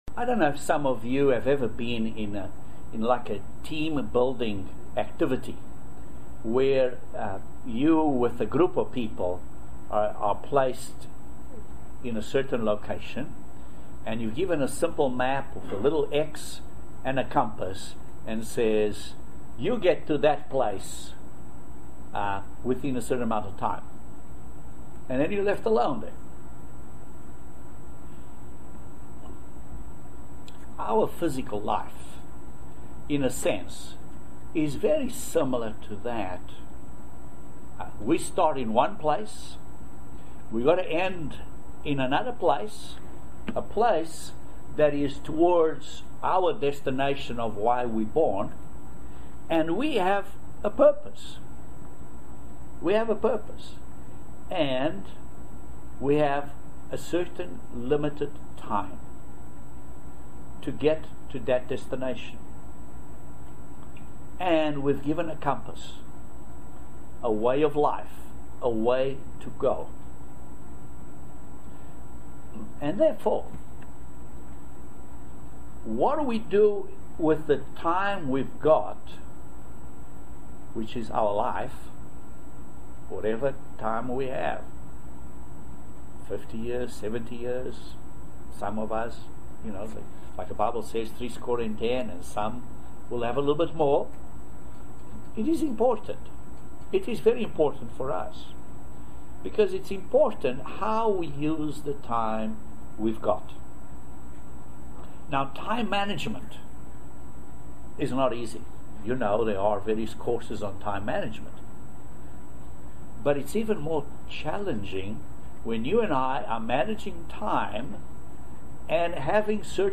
Very interesting Video sermon about practicing God's righteousness , and striving on to perfection. Keeping a clear vision and keeping it in the front of our minds, Join us for this interesting study of how to get to our goal of practicing God's righteousness.